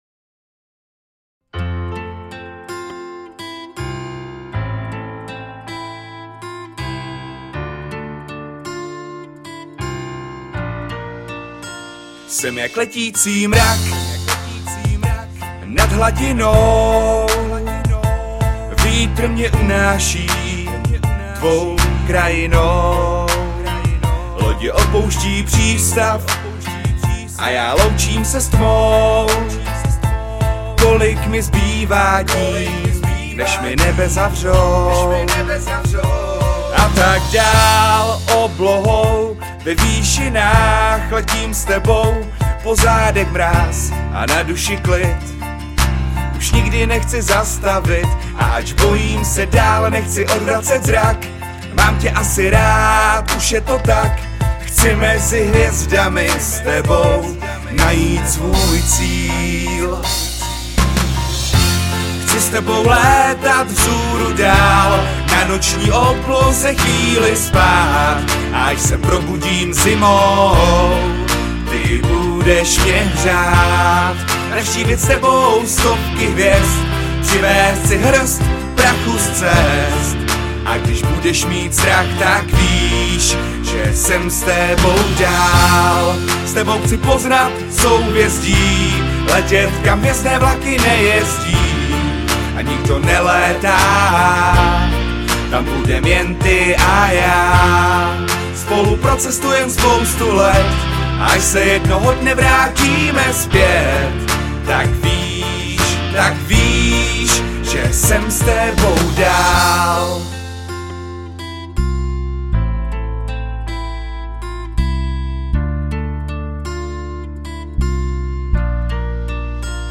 Žánr: Pop/Rock/Folk